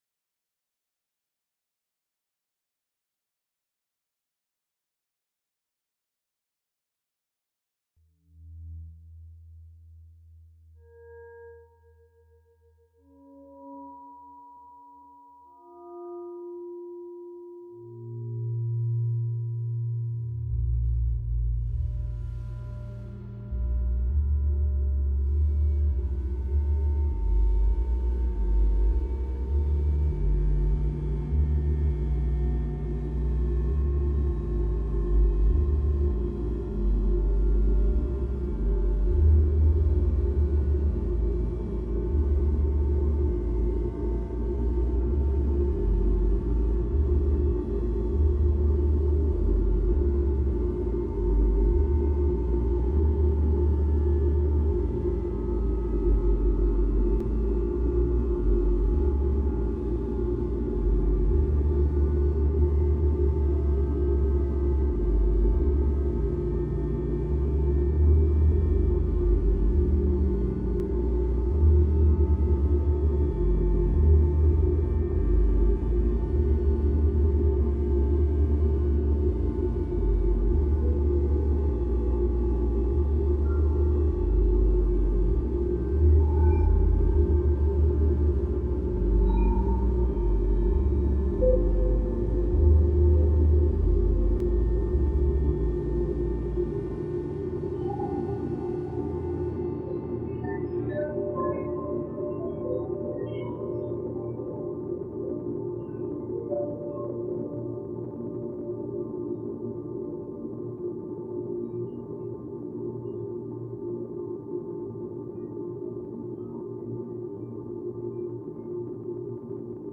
I think it must be possible creating spatial representations by sounds.